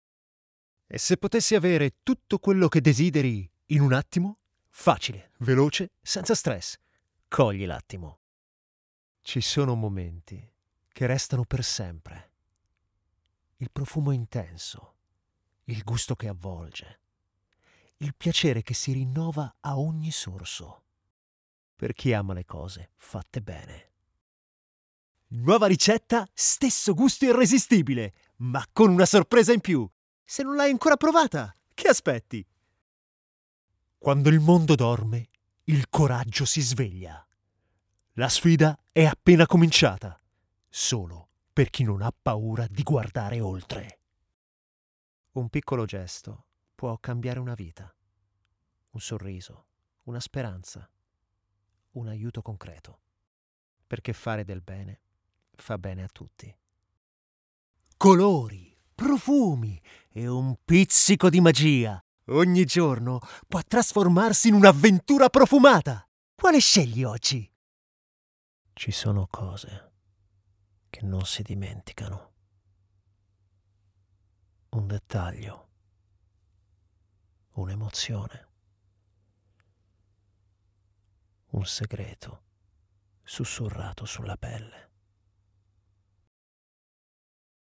Demo Voce Italiano 2025